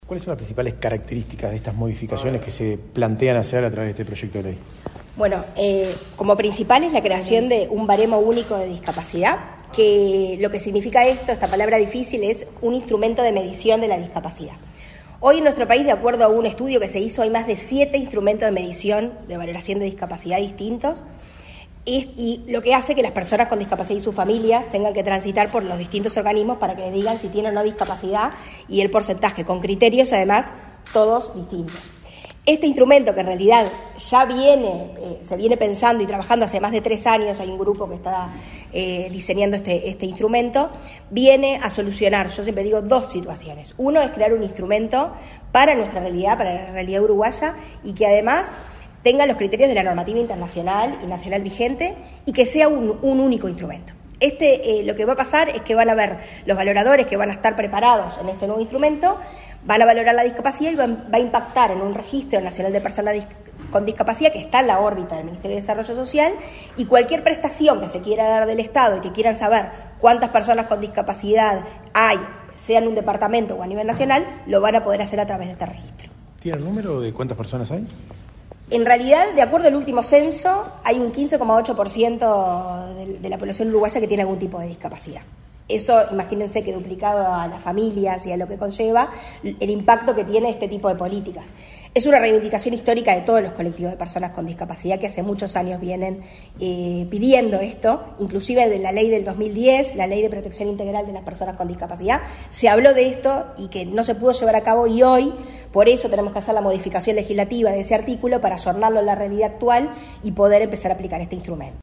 Declaraciones a la prensa de la directora de Discapacidad del Mides, Karen Sass
El Poder Ejecutivo, a través del Ministerio de Desarrollo Social (Mides) y con el apoyo de la Presidencia de la República, presentó al Parlamento un proyecto de ley para crear un baremo único nacional de discapacidad y un Consejo Asesor de Expertos en Instrumentos de Medición de Discapacidad. Acerca del tema, la directora de Discapacidad del Mides, Karen Sass, realizó declaraciones a la prensa.